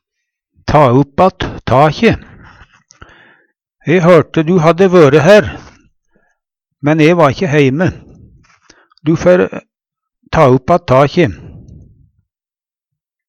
ta upp att takje - Numedalsmål (en-US)
ta-upp-att-takje.mp3